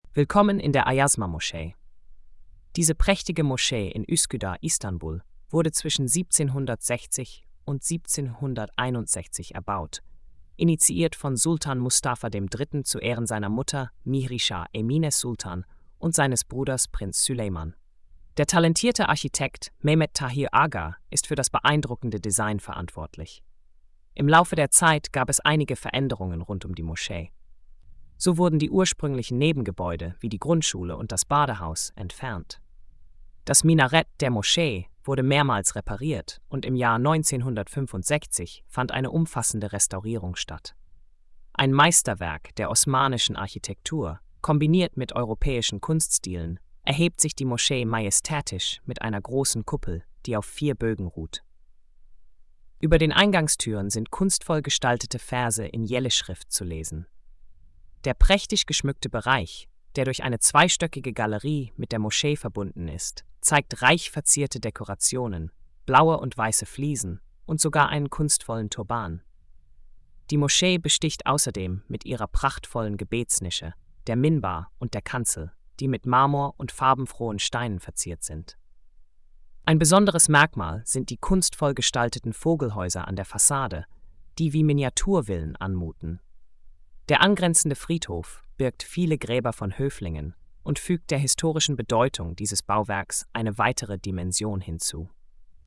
Hörfassung des inhalts: